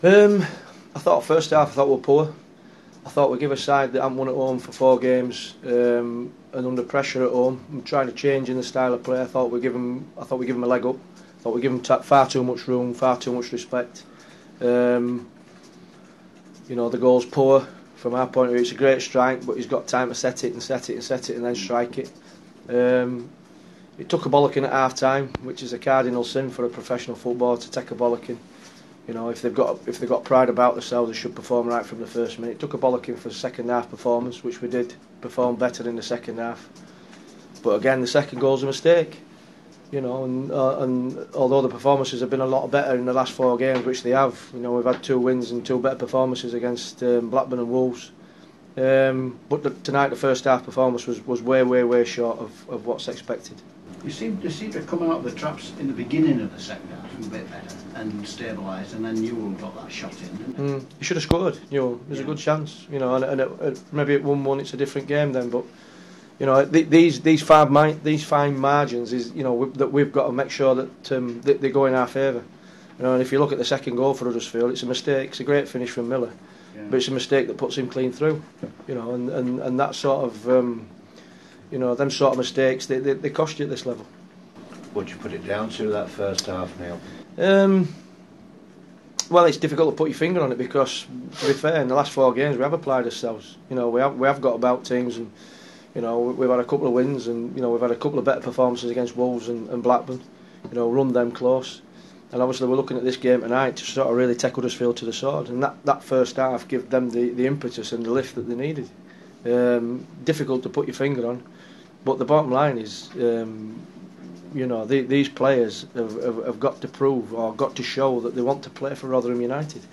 The Rotherham United manager Neil Redfearn gave his thoughts to the press after the 2-0 defeat to Huddersfield Town.